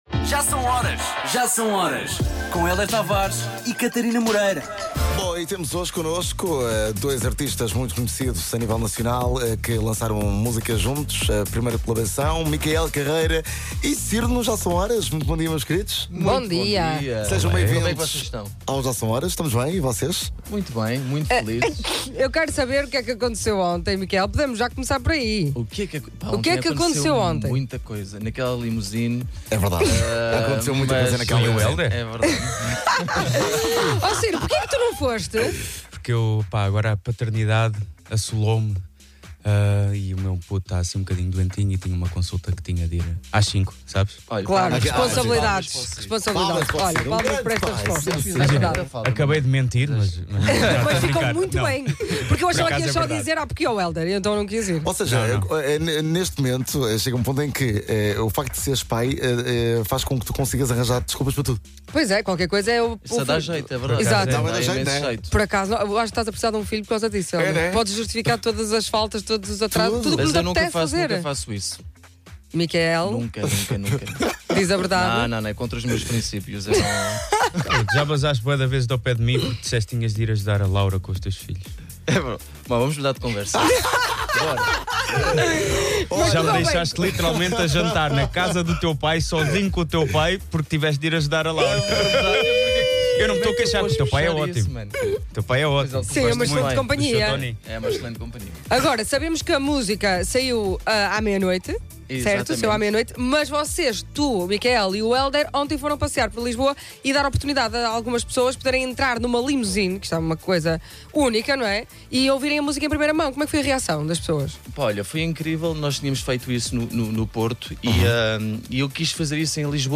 entrevista_mickael_carreira_syro_d5c57f0d_normal.mp3